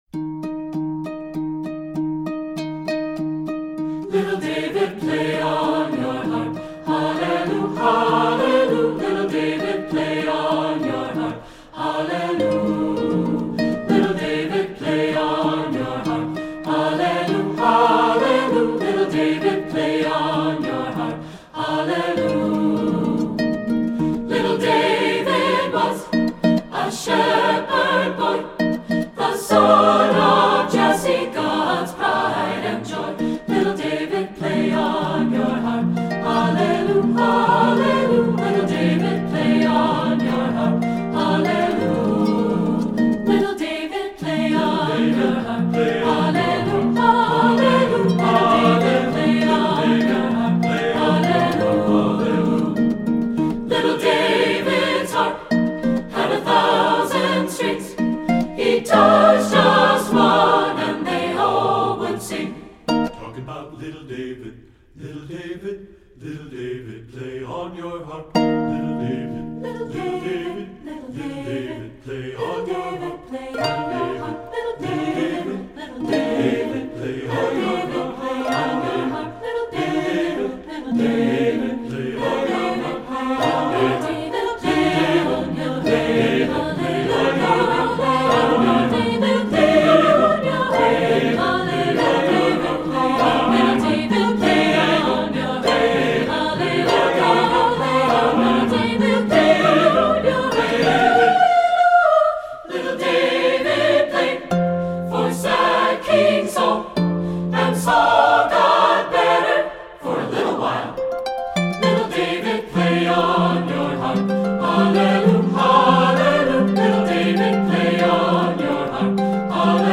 Composer: Spiritual
Voicing: SATB and Piano